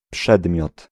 Ääntäminen
Synonyymit obiekt Ääntäminen Tuntematon aksentti: IPA: /ˈpʂɛdmjɔt/ Haettu sana löytyi näillä lähdekielillä: puola Käännös Ääninäyte Substantiivit 1. subject 2. thing US 3. object US US Suku: m .